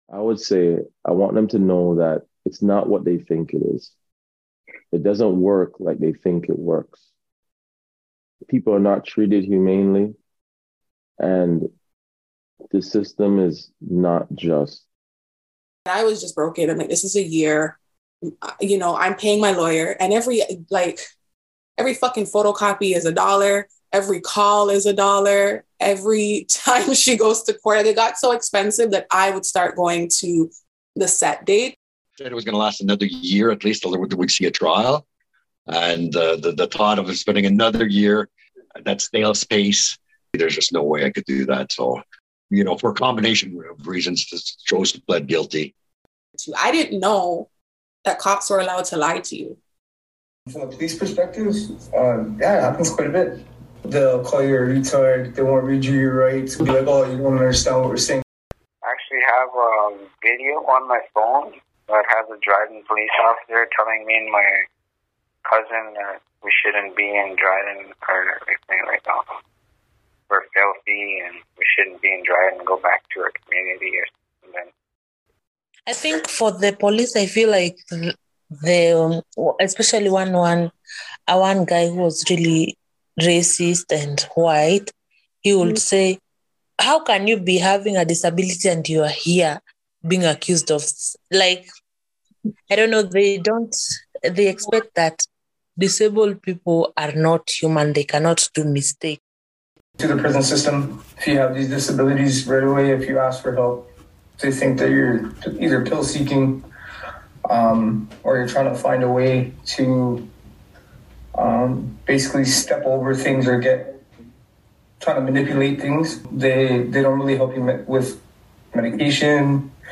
Dispatches: An initial collection of voices from our first handful of interviews with prisoners speaking about prison, disability, and what they want us to know.